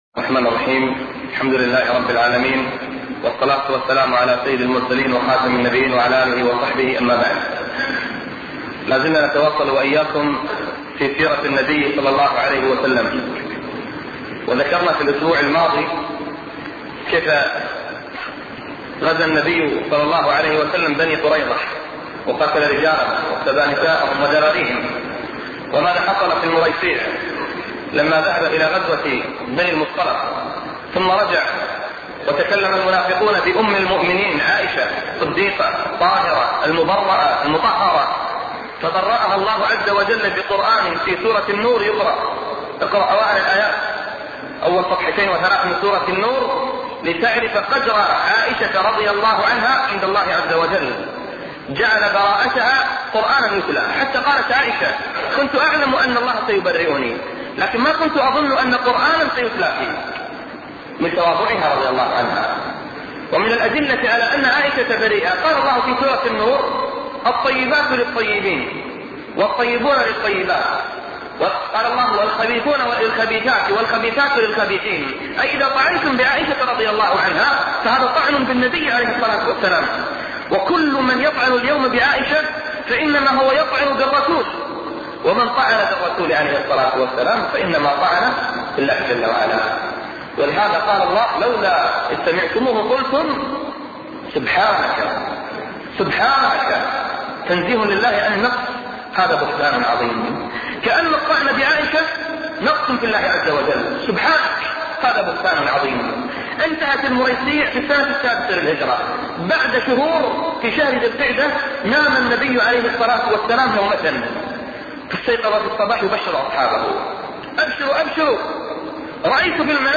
أرشيف الإسلام - ~ أرشيف صوتي لدروس وخطب ومحاضرات الشيخ نبيل العوضي